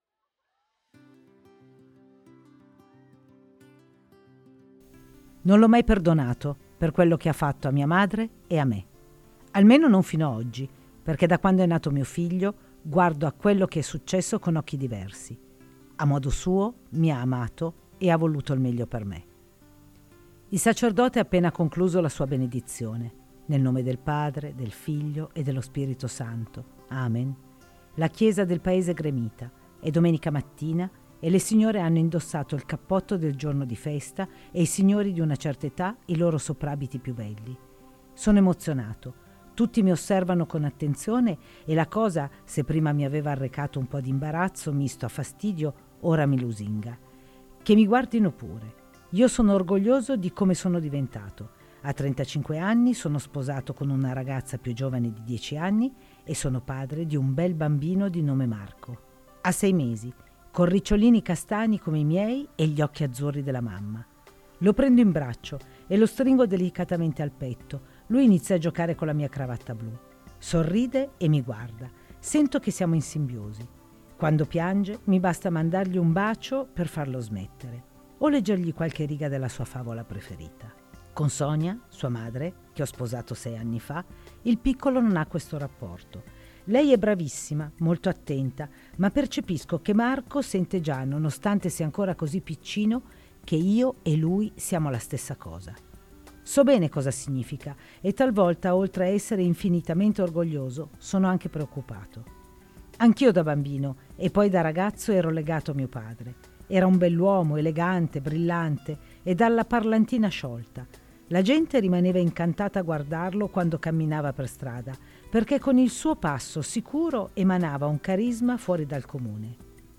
Music under courtesy of Forte Media & Consulting Sagl